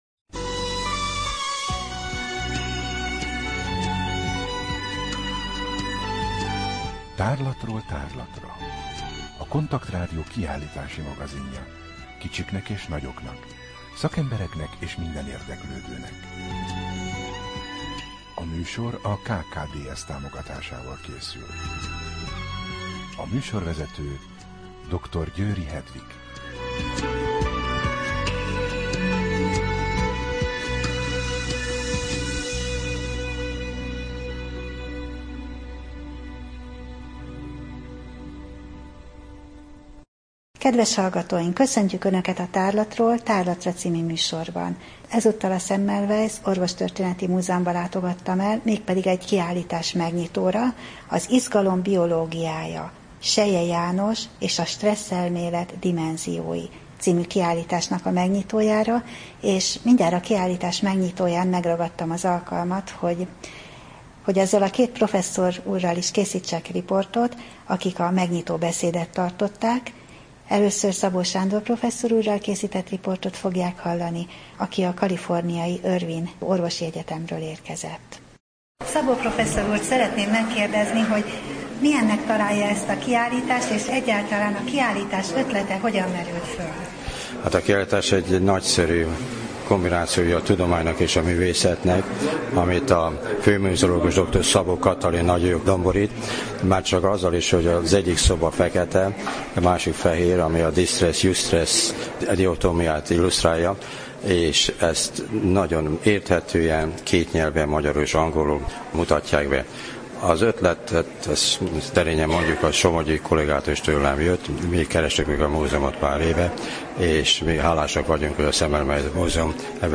Rádió: Tárlatról tárlatra Adás dátuma: 2015, Május 7 Tárlatról tárlatra / KONTAKT Rádió (87,6 MHz) 2015. május 7. A műsor felépítése: I. Kaleidoszkóp / kiállítási hírek II.